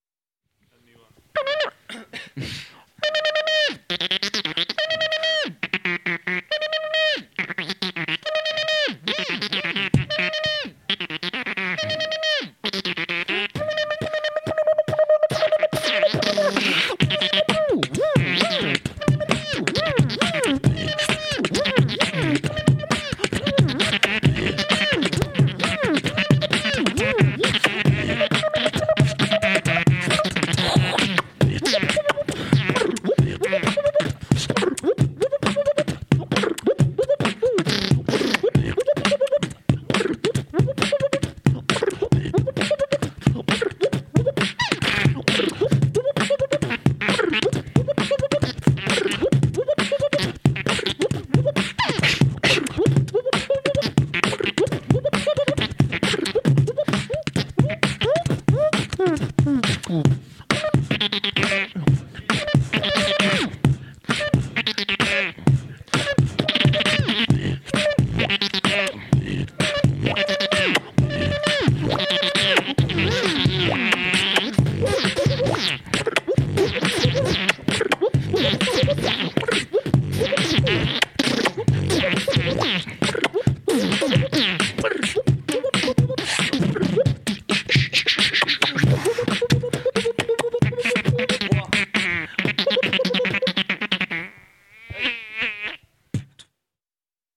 Jam beatboxowy + piękne wokale kobiece + berimbau + harmonijka ustna.